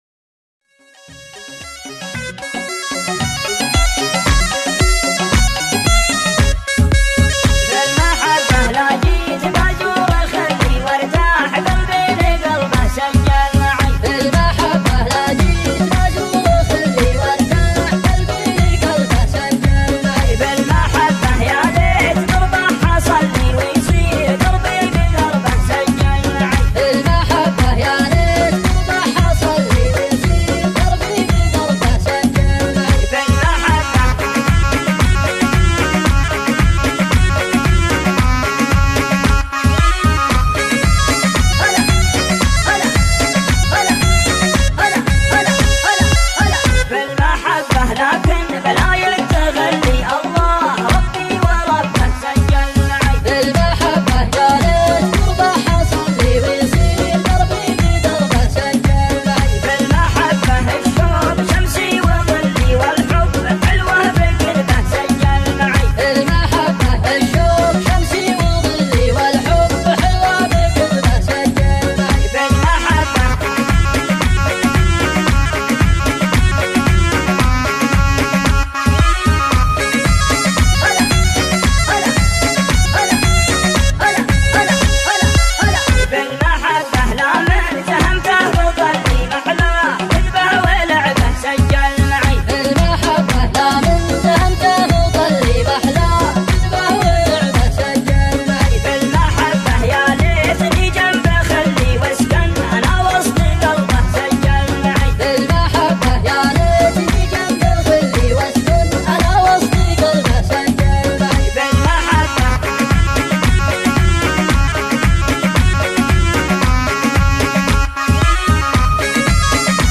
شيلات طرب / شيلات مسرعة